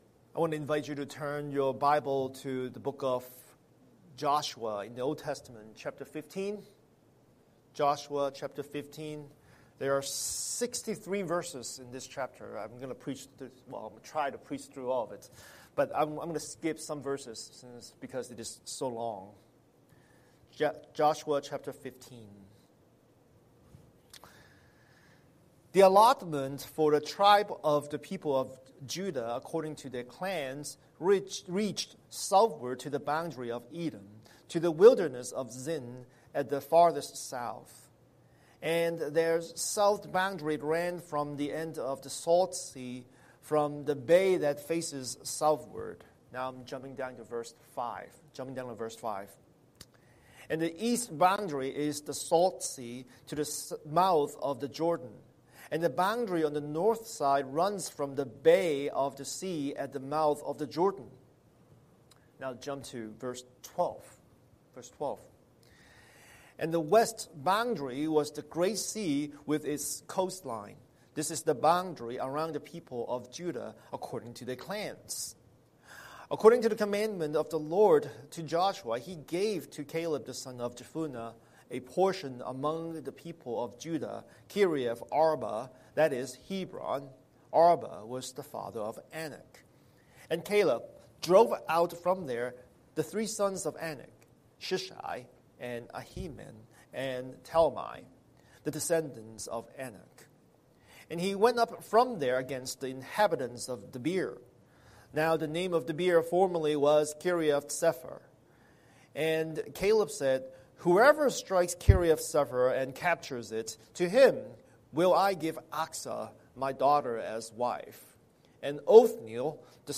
Scripture: Joshua 15:1-63 Series: Sunday Sermon